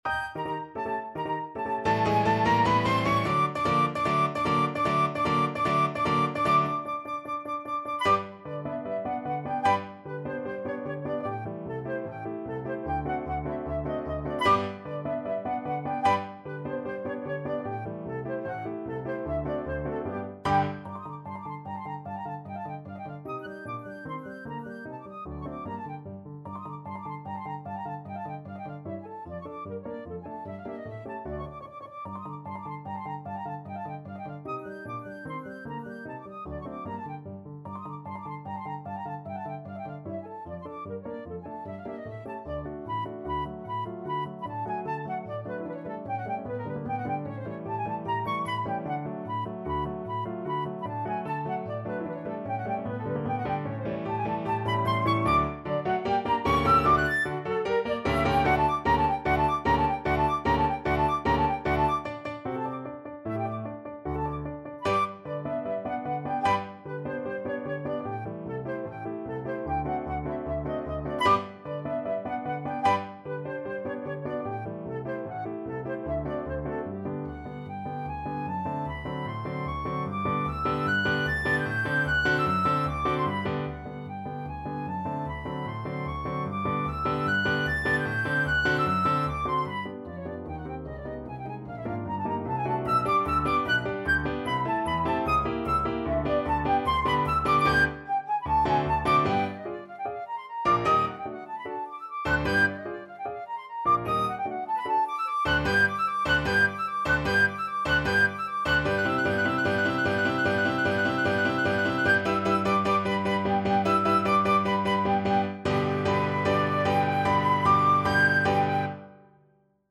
Classical Ponchielli, Amilcare Galop from Dance of the Hours (from La Gioconda) Flute version
Flute
2/4 (View more 2/4 Music)
Allegro vivacissimo ~ = 150 (View more music marked Allegro)
G major (Sounding Pitch) (View more G major Music for Flute )
Classical (View more Classical Flute Music)